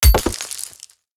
axe-mining-ore-2.ogg